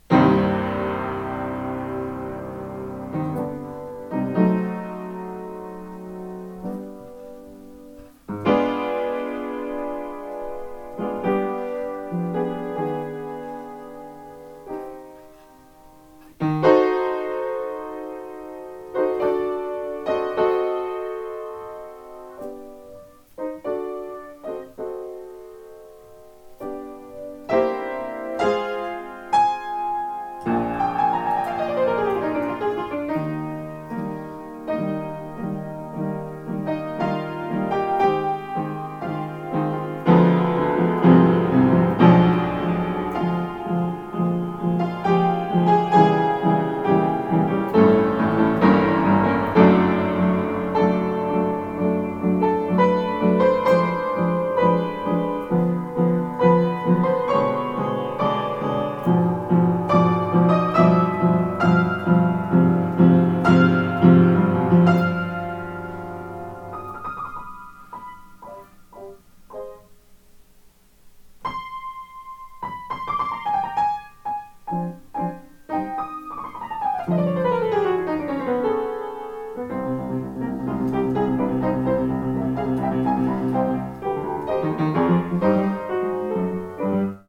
Le tempo fluctue toujours beaucoup, et tu accélères encore (même si tu accélères moins qu’avant).
Des tas de grands pianistes accélèrent dans ce début, on est pas obligé de jouer tout a tempo si ?